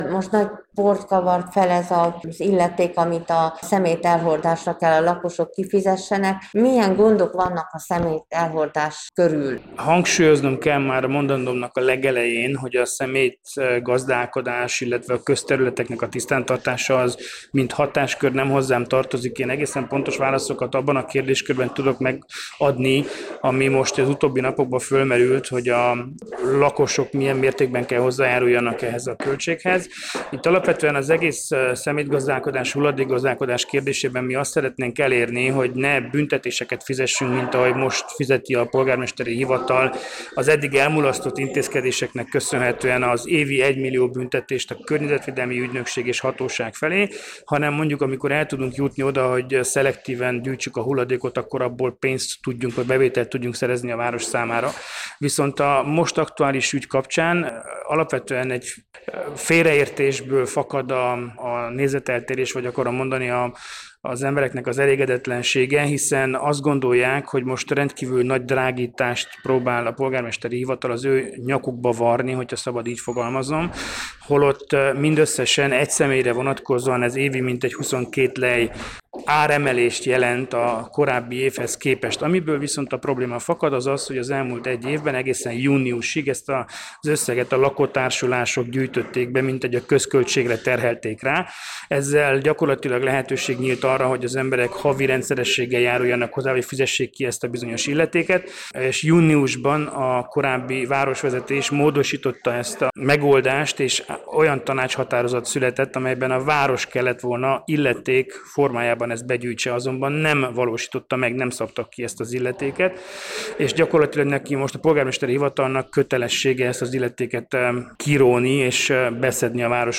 Portik Vilmos alpolgármester nyilatkozott rádiónknak.